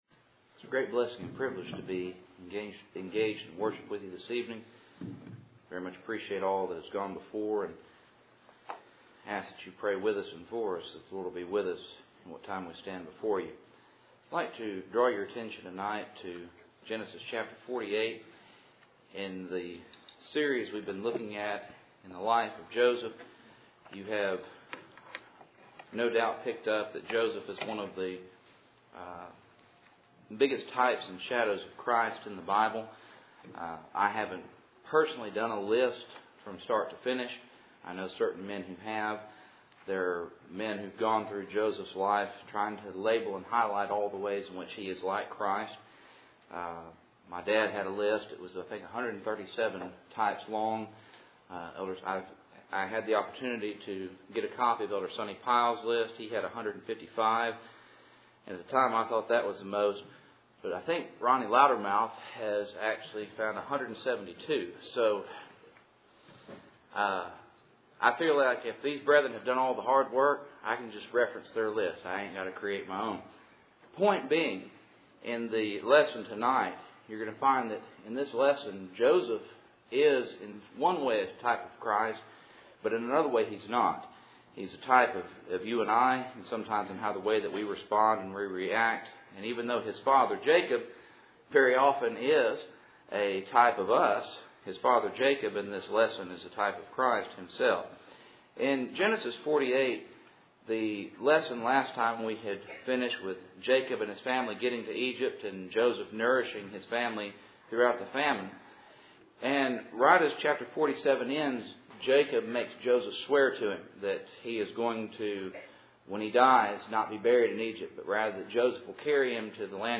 Preacher
Sunday Evening